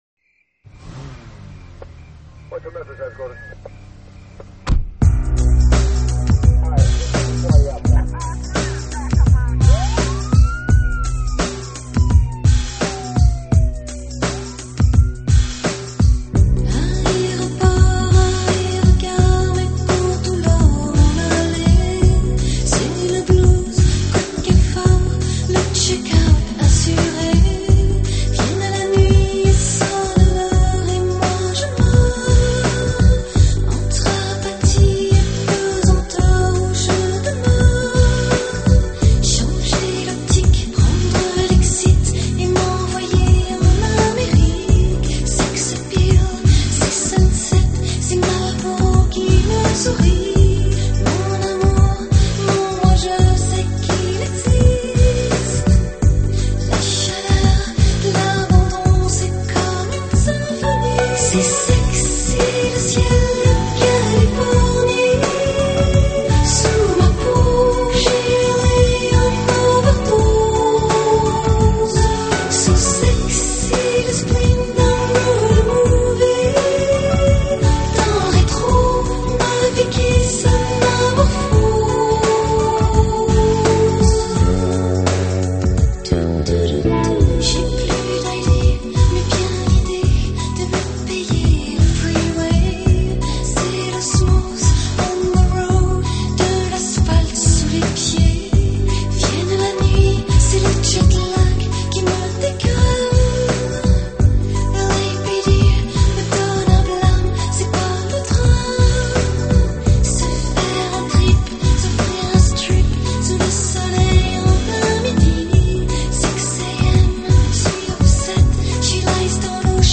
French Pop/Chanson/Dance
她的歌声另类、却富亲和力，穿透力极强，宛如天籁。